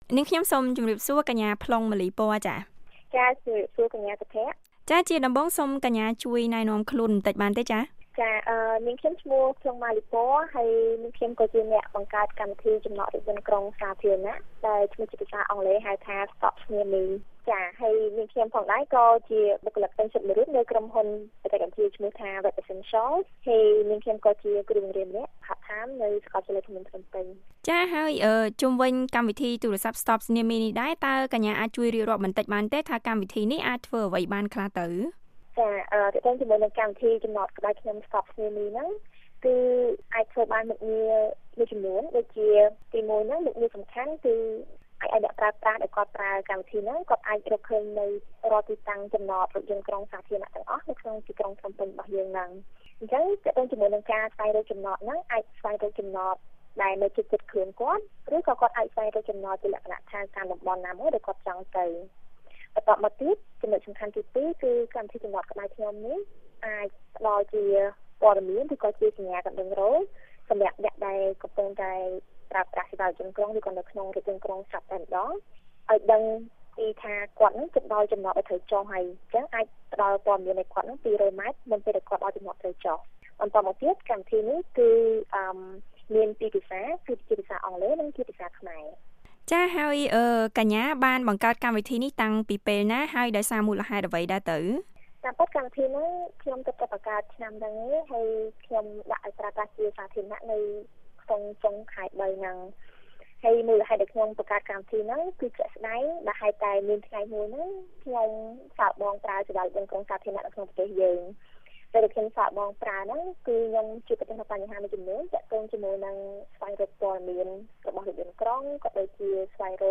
បទសម្ភាសន៍
បទសម្ភាសន៍ VOA៖ យុវតីខ្មែរបង្កើតកម្មវិធីទូរស័ព្ទដើម្បីសម្រួលការប្រើរថយន្តក្រុងសាធារណៈ